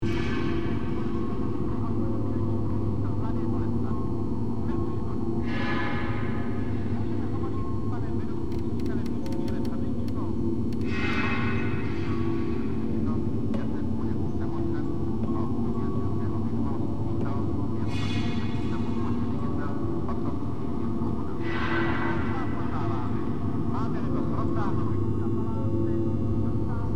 Download Free Horror Sound Effects | Gfx Sounds
Derelict-asylum-ambience-exploration-loop.mp3